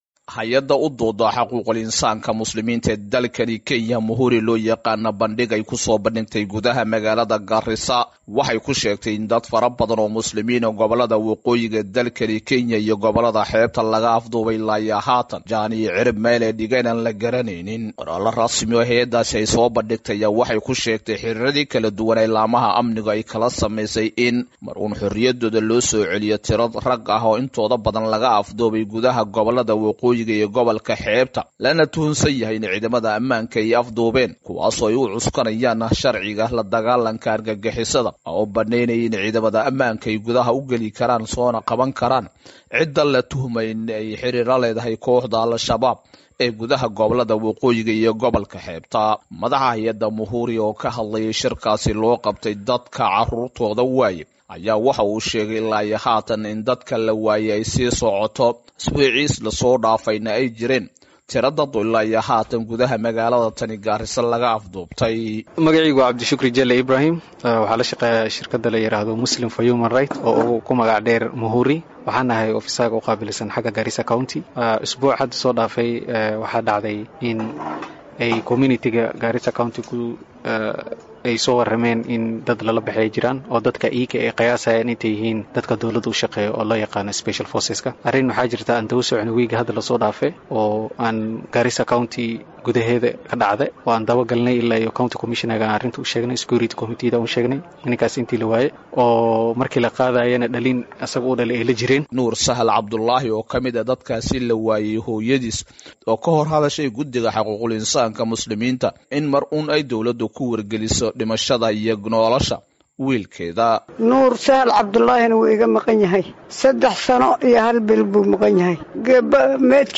Warbixin arrintan ku saabsan waxaa inoo soo direy wariyaheena